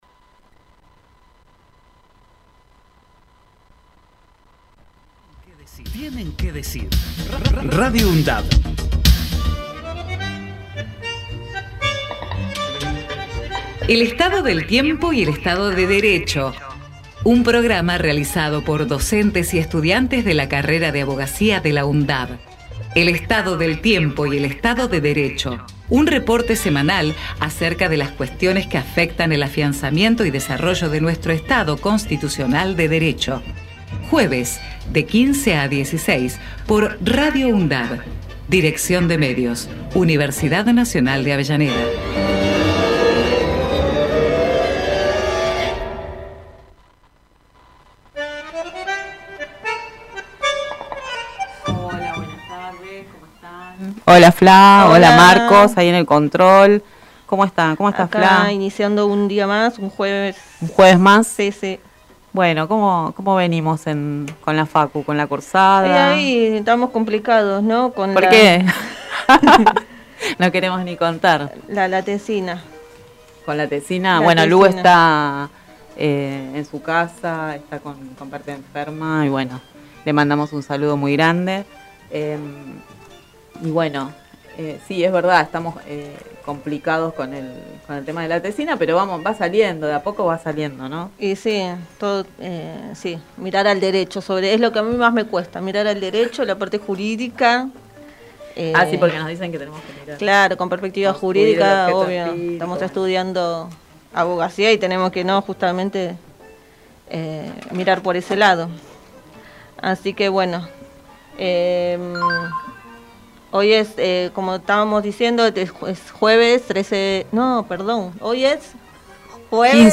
Por otra parte, " El Estado del Tiempo y el Estado de Derecho , es un programa que aborda temas del ámbito del Derecho y se propone funcionar como un estado meteorológico jurídico.